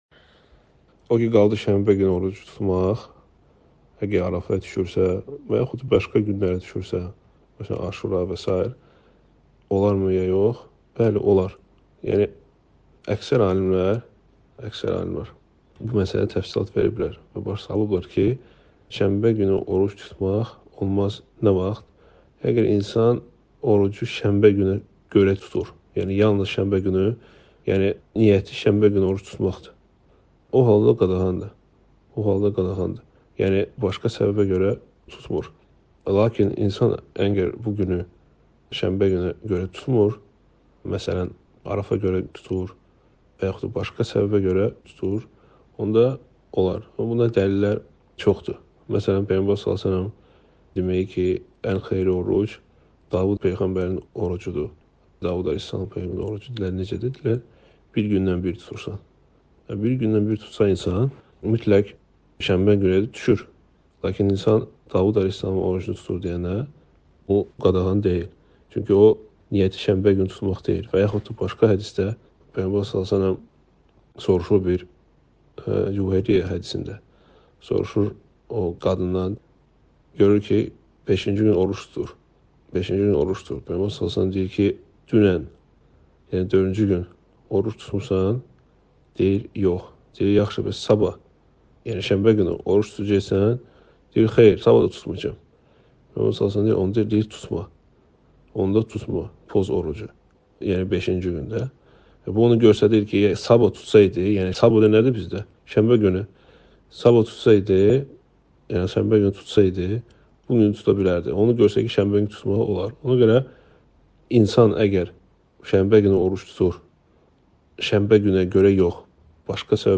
Sual-Cavab